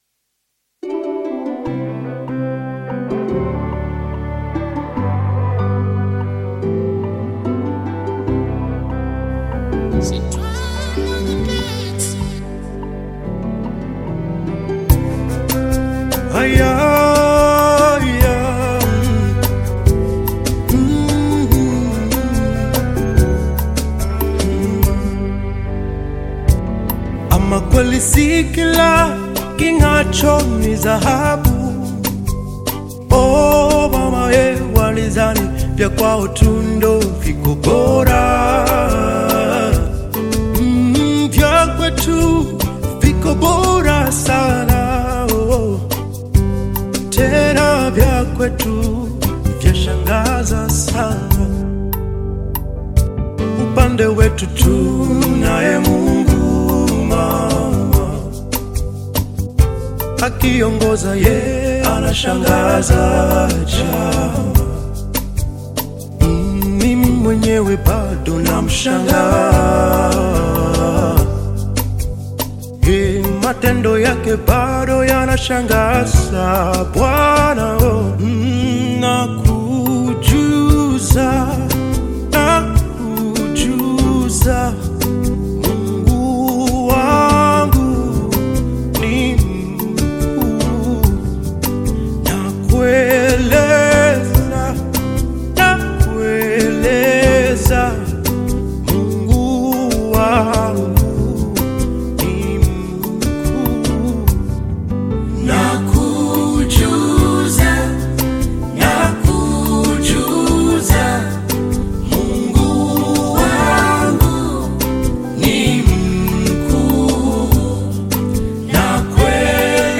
Gospel music track
Gospel song